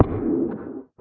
guardian_hit3.ogg